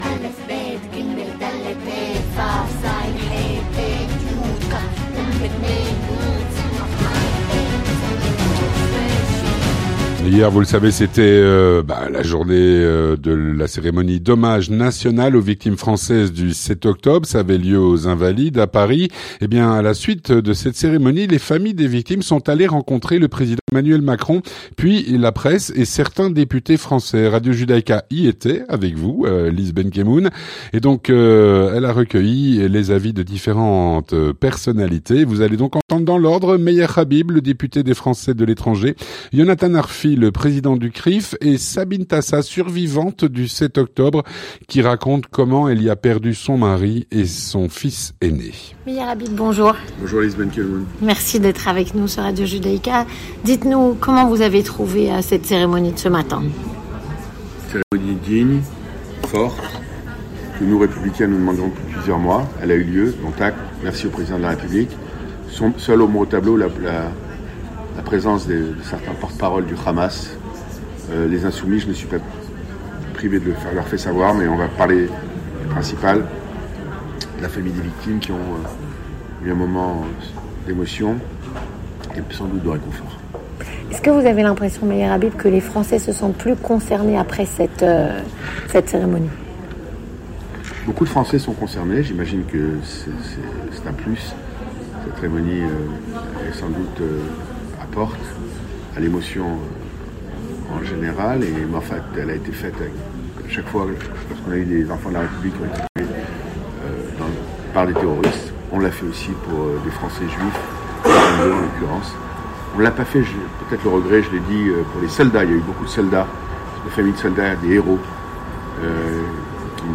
Radio Judaica y était.
Témoignages